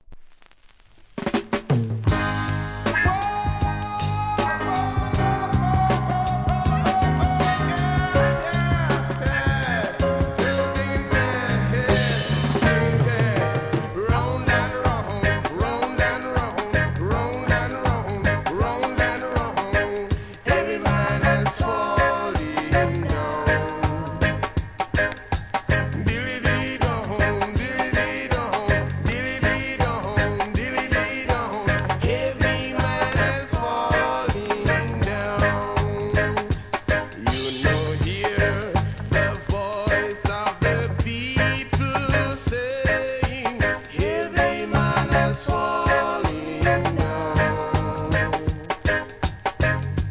REGGAE
FUNKYなイントロが印象的な1976年作品!!